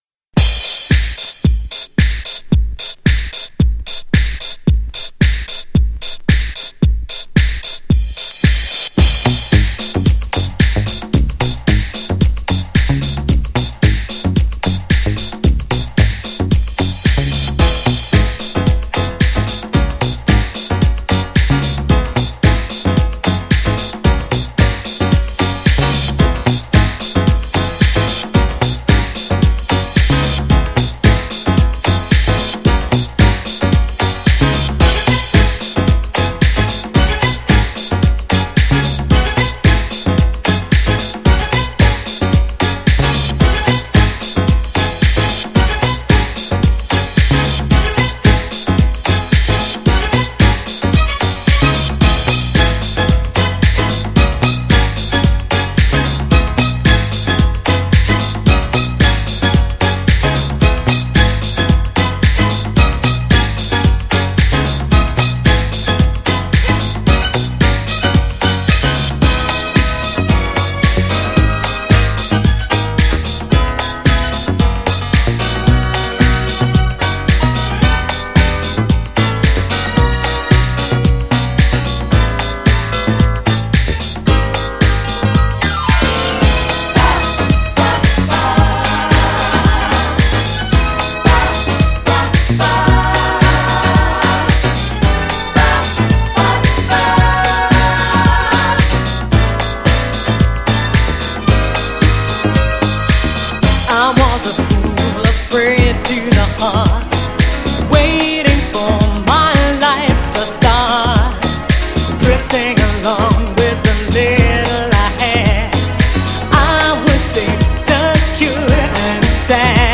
ピアノのイントロで盛り上がってサビは大合唱間違いなし!
GENRE R&B
BPM 106〜110BPM